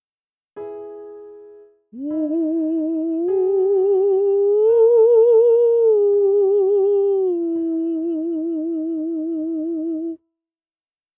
音量注意！
training-strength-falsetto-01.mp3